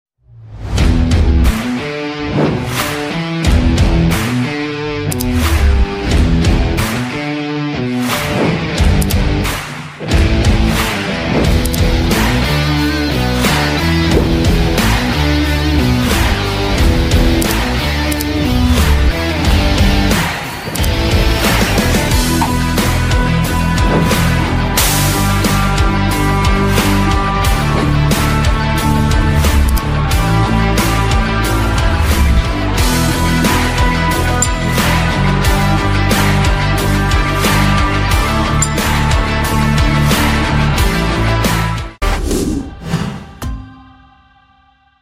Revolutionize your safety soundtrack with Abrams White Noise Backup Alarm! 🎶🚦 Beep, Beep, Back Up Safely!